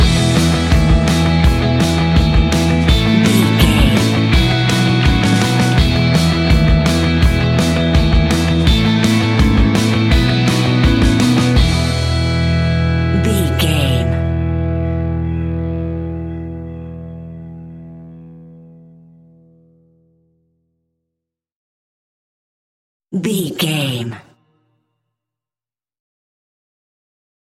Green Day Soundalike 15 Sec.
Ionian/Major
pop rock
energetic
uplifting
instrumentals
indie pop rock music
upbeat
groovy
guitars
bass
drums
piano
organ